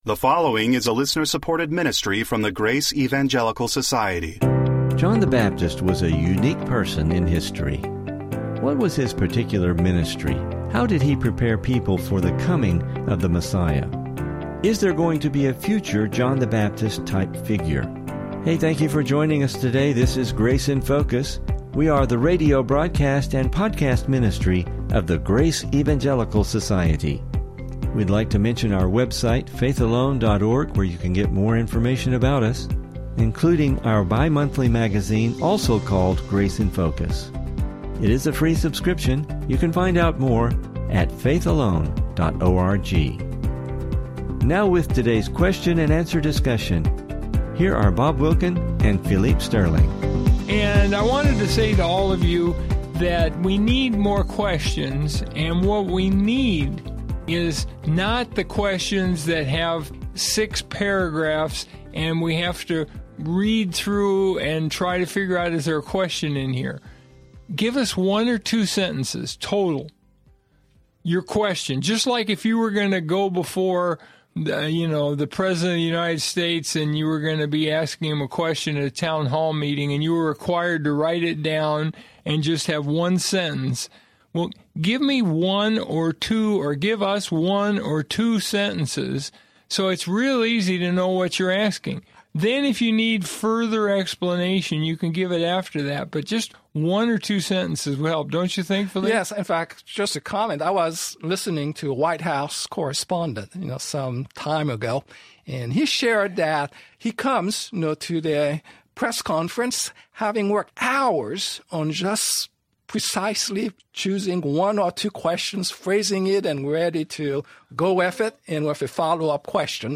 Is there going to be a future John the Baptist-like figure? Please listen for some interesting Biblical discussion regarding this subject!